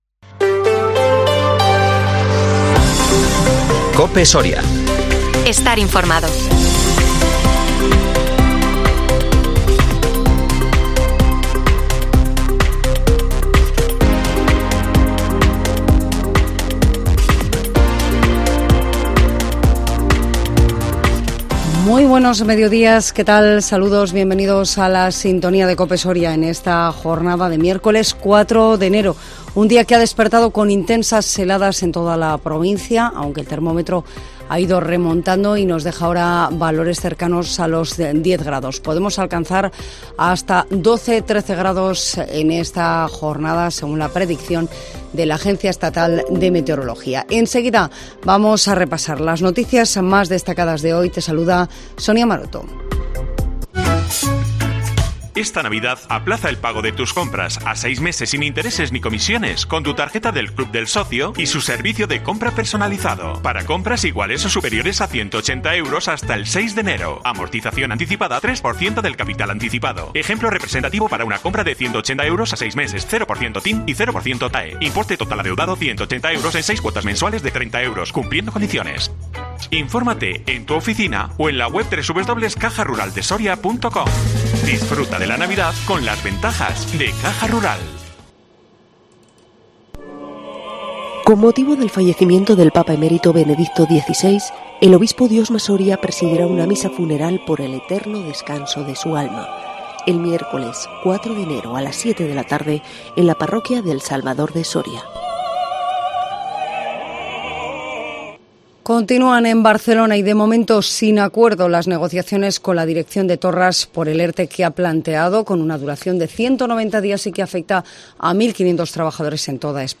INFORMATIVO MEDIODÍA COPE SORIA 4 ENERO 2023